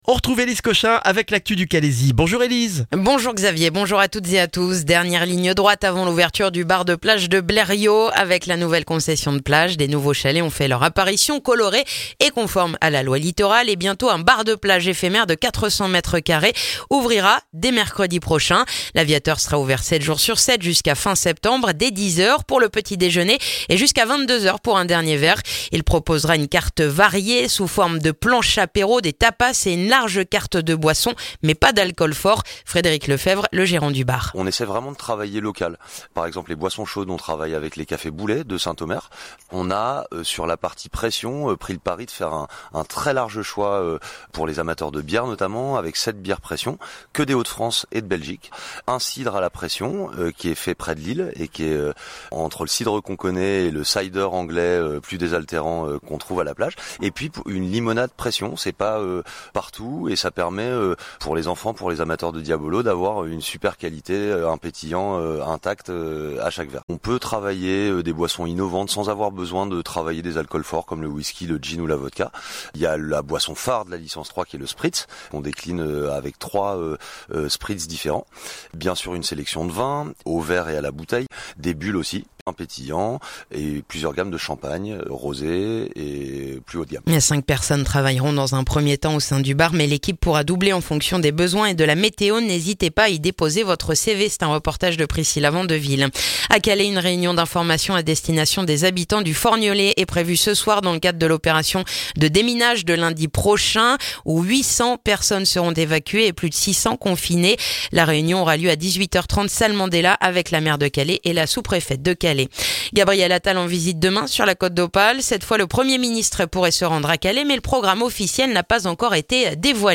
Le journal du jeudi 16 mai dans le calaisis